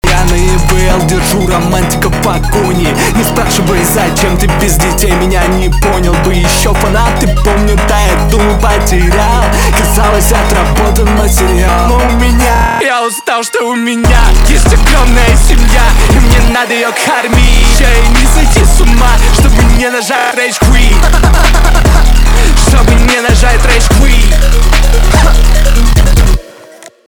русский рэп
басы
жесткие , электроника